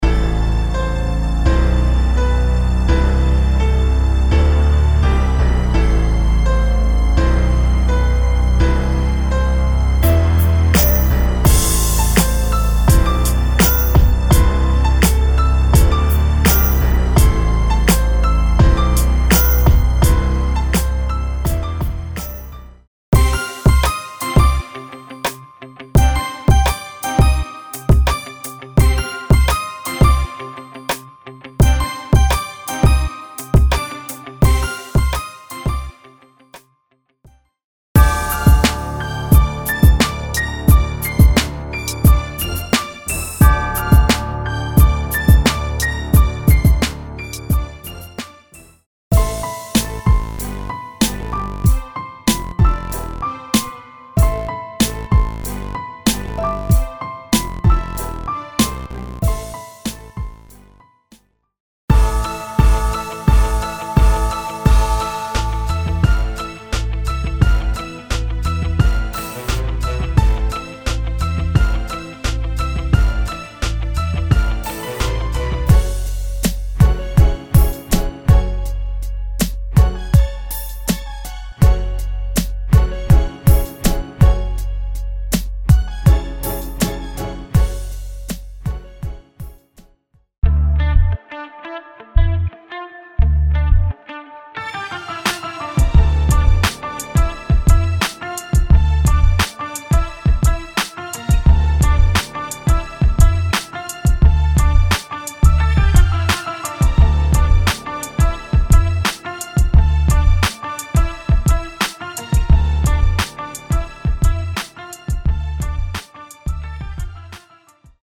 Download Loops and Samples 73 to 97 b Bpm